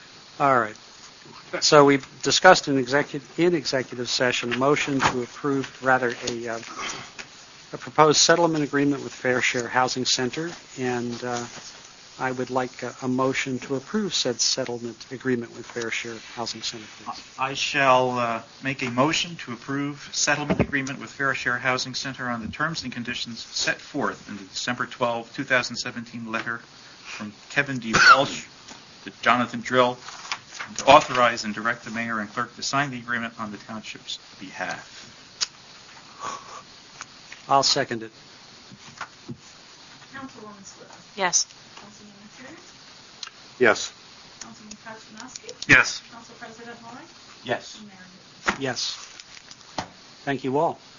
On Dec. 13, 2017, at the last Clinton Township council meeting of the year, in the last minute of the meeting, Mayor John Higgins — who just got re-elected — and the council took exactly 51 seconds to vote to sign a legal settlement deal with the Fair Share Housing Center (FSHC) obligating the town to add 805 new housing units at any cost necessary to Clinton Township.